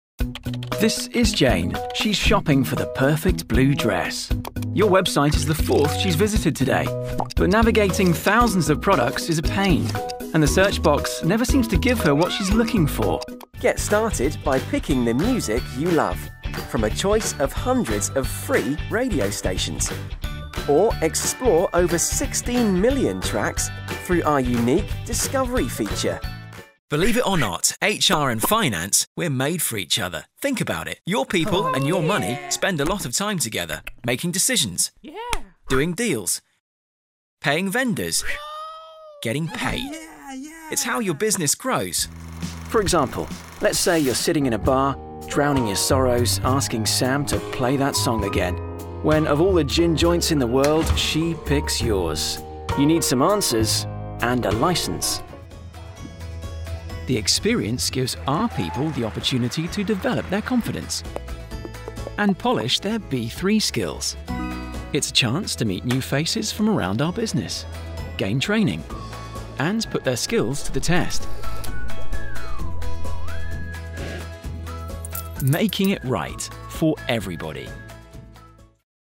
Englisch (Britisch)
Junge, Natürlich, Verspielt, Zugänglich, Freundlich
Erklärvideo